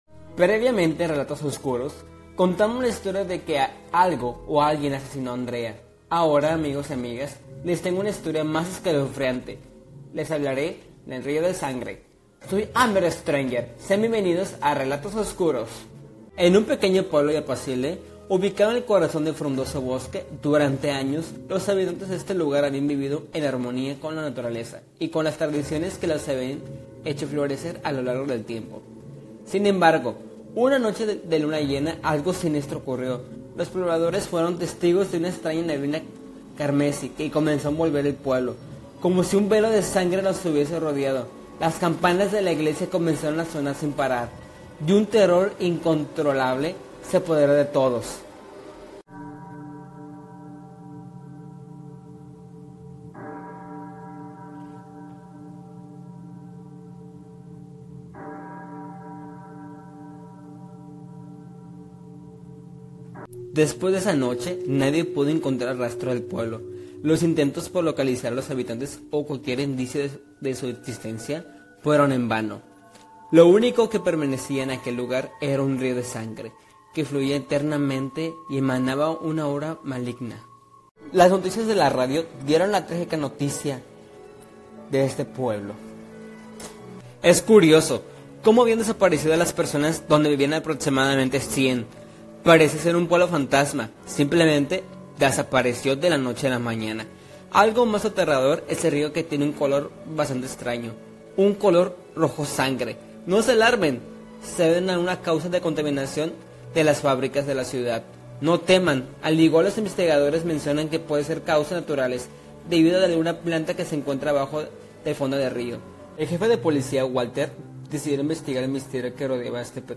Format: Audio Book
Voices: Solo
Genres: Horror